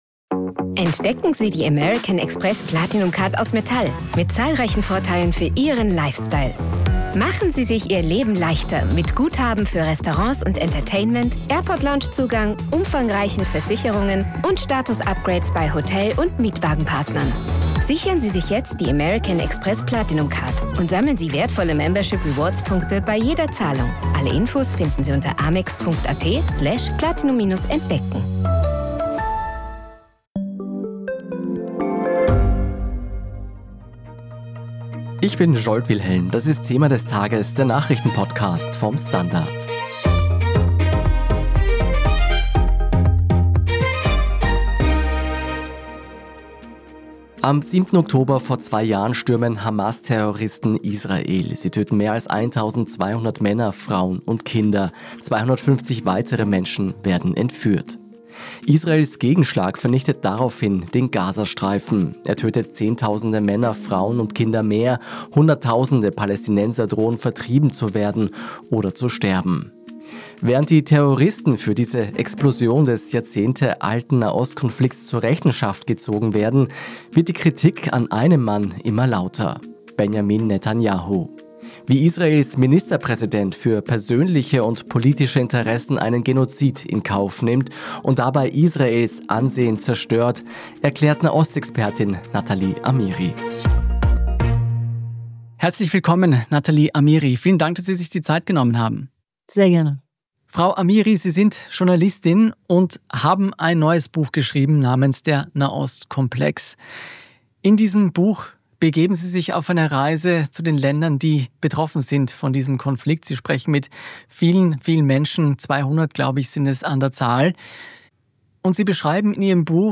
Natalie Amiri ist eine deutsch-iranische Journalistin und Nahost-Expertin.